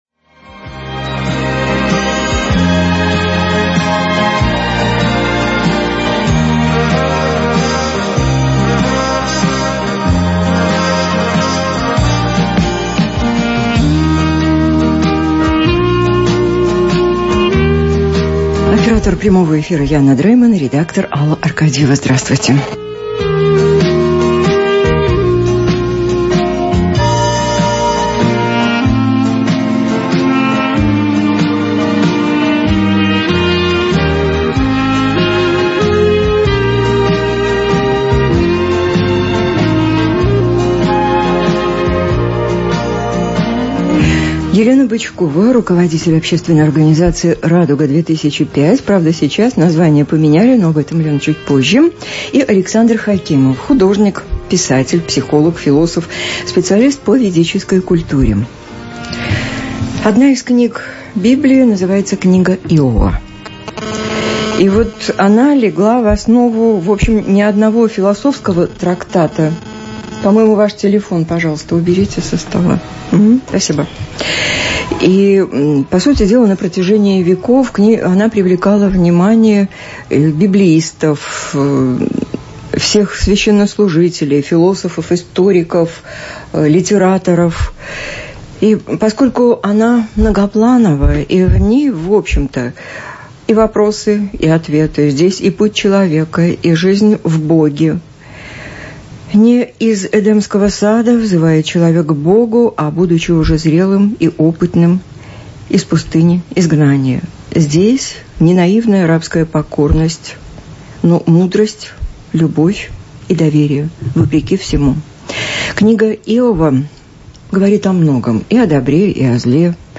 Диалог. Прямой эфир на радио Домская Площадь (2016, Рига)